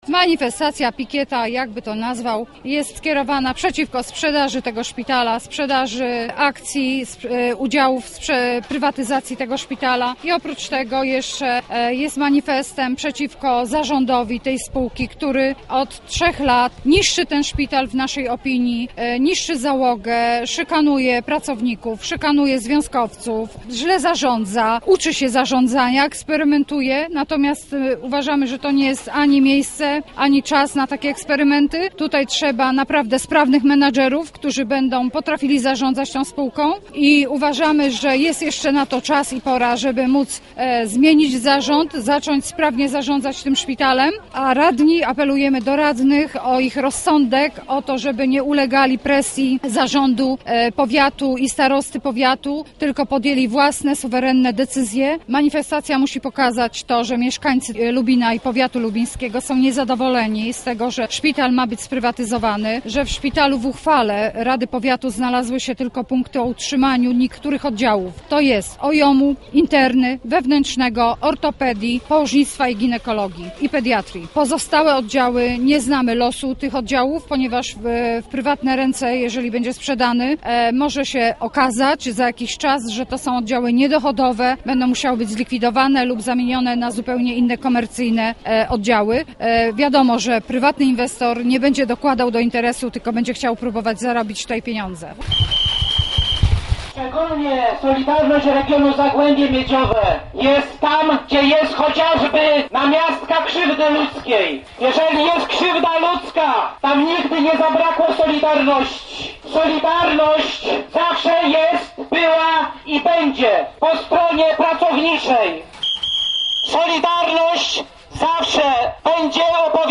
To było gorące popołudnie na ulicach Lubina. Kilkadziesiąt os�b uczestniczyło w manifestacji przeciwko planom sprzedaży Regionalnego Centrum Zdrowia.
Były głośniki, gwizdki, transparenty z hasłami i związkowe flagi.